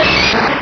Cri de Machoc dans Pokémon Rubis et Saphir.
Cri_0066_RS.ogg